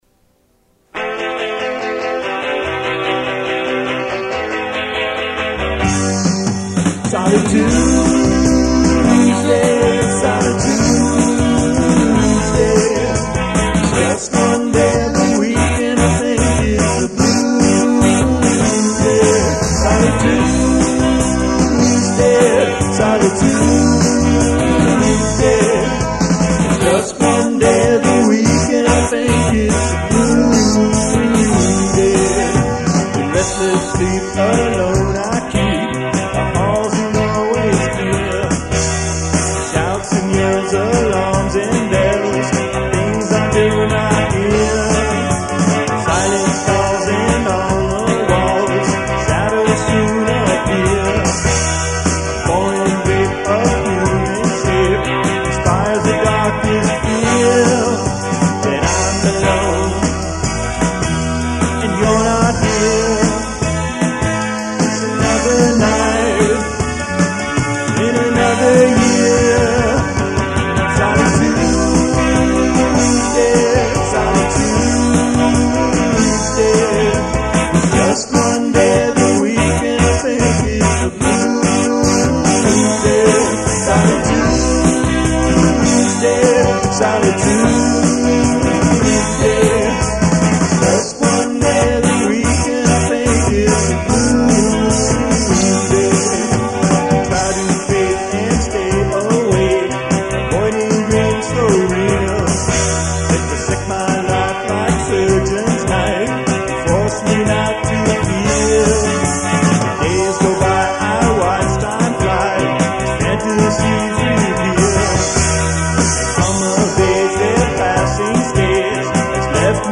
vocals & guitar
drums